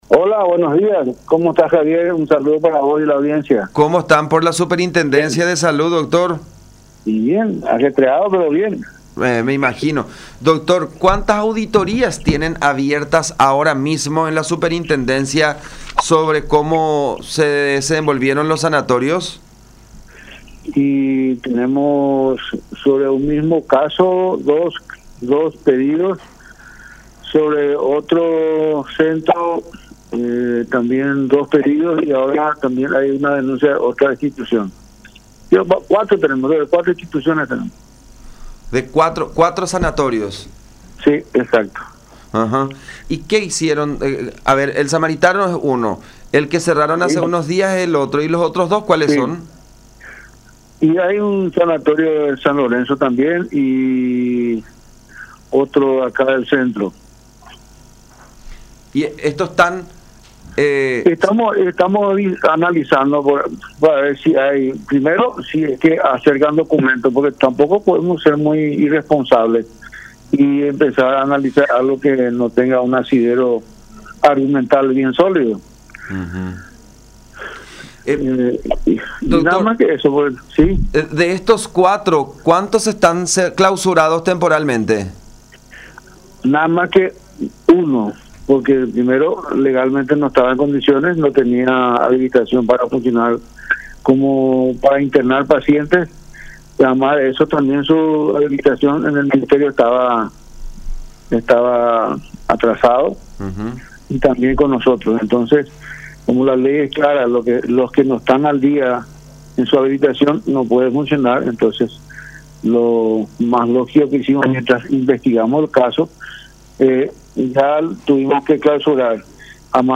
“Tenemos en total 4 sanatorios que están siendo intervenidos mediante auditorías. Estamos analizando todas las aristas posibles, a fin de no cometer ninguna equivocación, de acuerdo a las documentaciones que fuimos solicitando”, dijo Ignacio Mendoza, superintendente de Salud, en conversación con Todas Las Voces por La Unión, aunque señaló que solamente uno está inhabilitado plenamente.